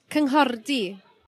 Welsh pronunciation) is a village in the rural community of Llanfair-ar-y-bryn in Carmarthenshire, Wales.